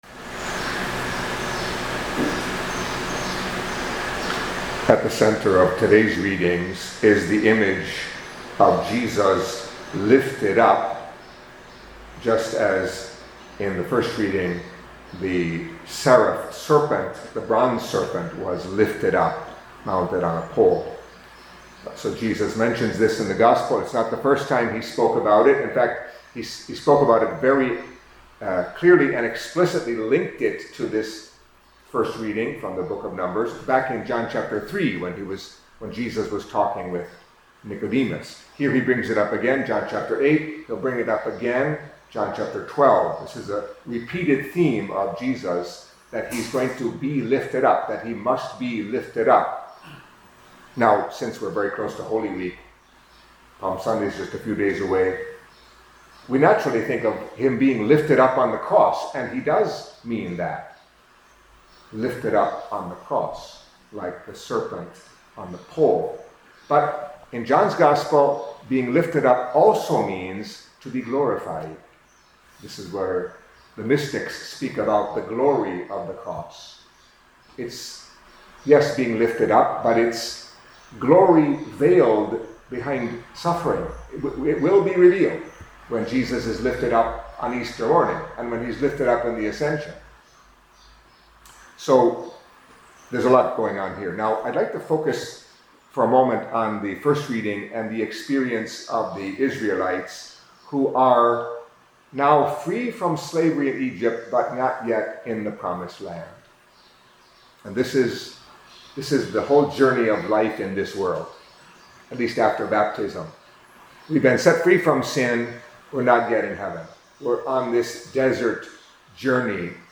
Catholic Mass homily for Tuesday of the Fifth Week of Lent